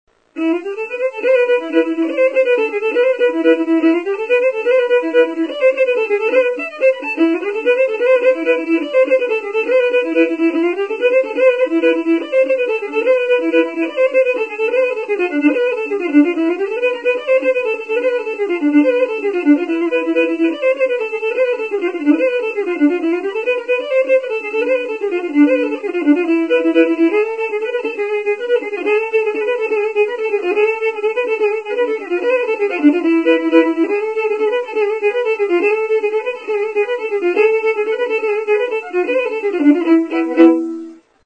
MUSIQUE >> Le Dauphiné, Rhône-Alpes
Violoneux : rigaudon